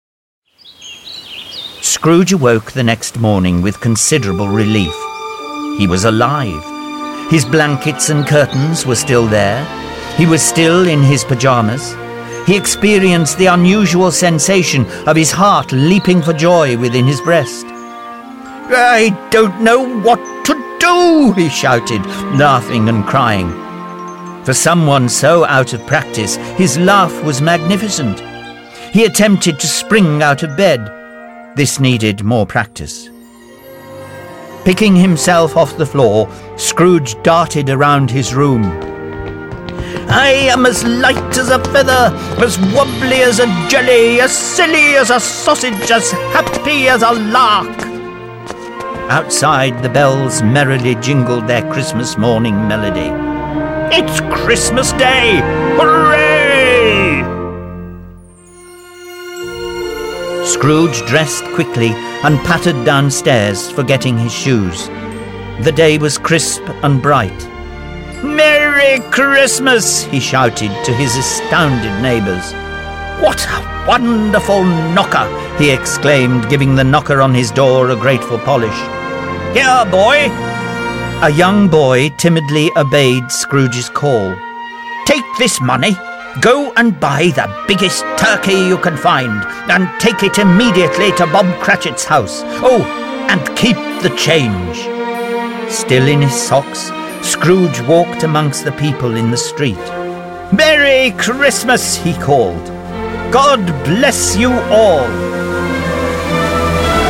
附贈英語朗讀故事CD，搭配情境音效更生動